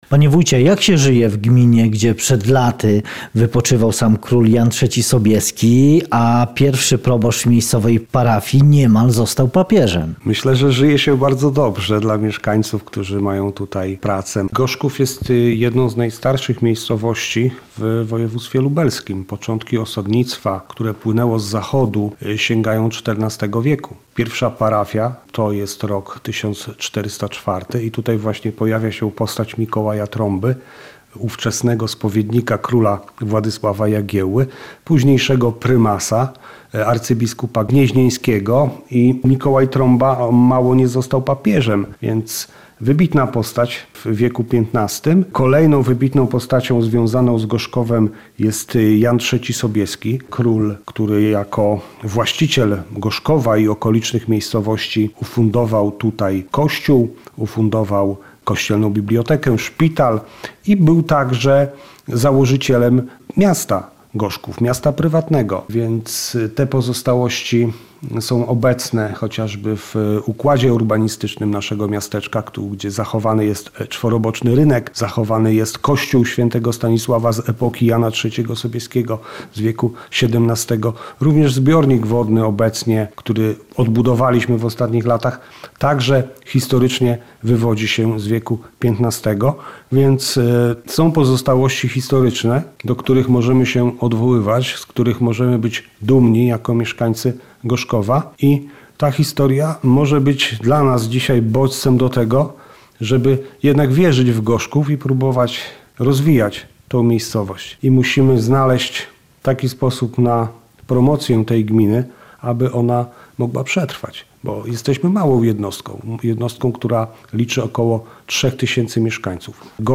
Rozmowa z wójtem gminy Gorzków Piotrem Cichoszem